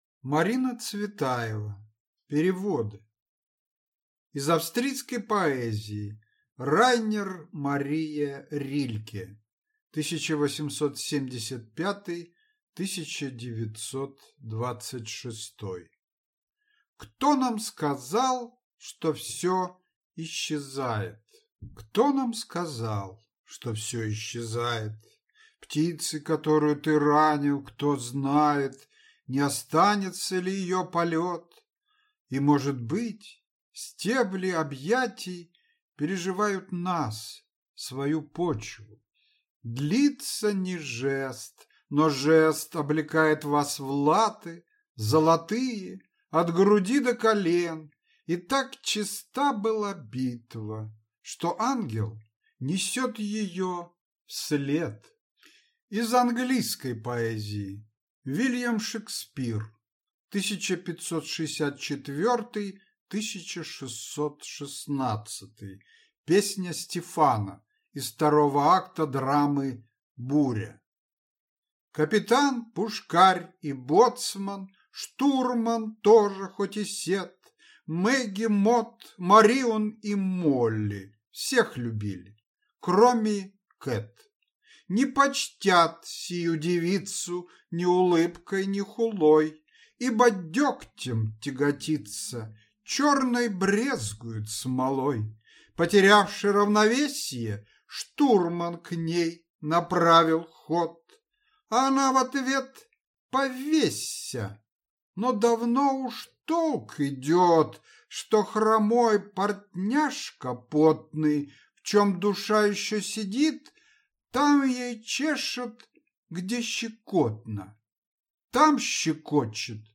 Аудиокнига Переводы | Библиотека аудиокниг